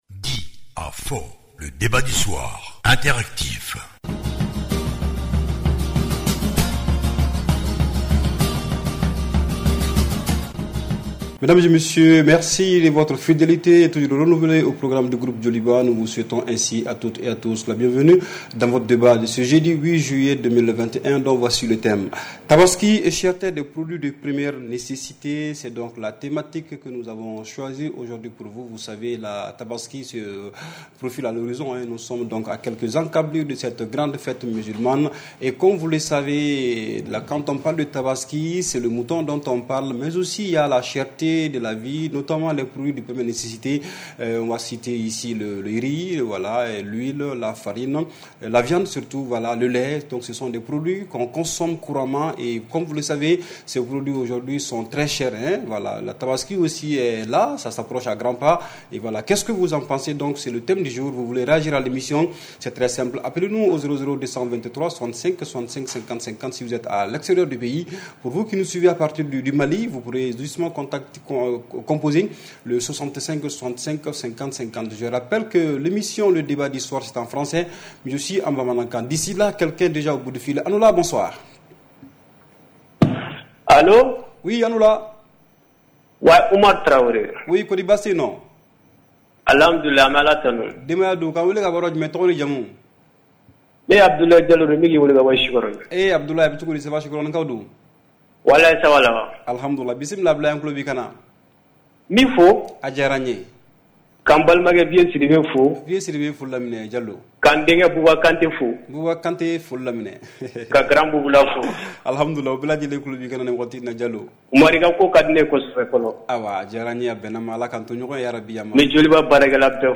REPLAY 08/07 – « DIS ! » Le Débat Interactif du Soir